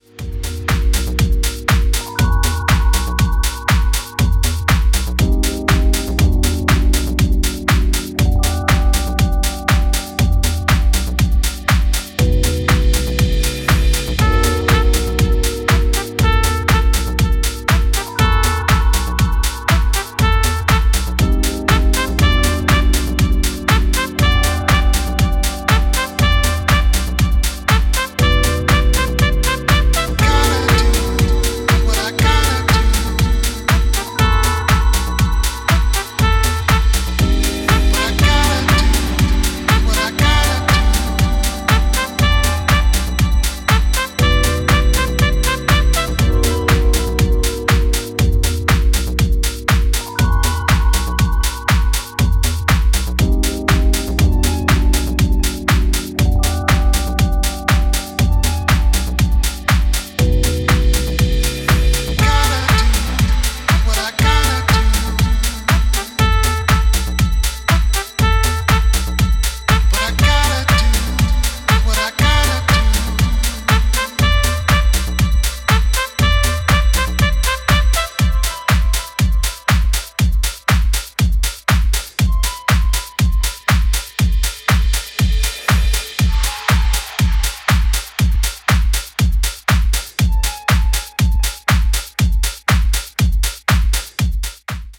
醸成されたムードがホーンのリフレインによって内省的な方面のピークを迎える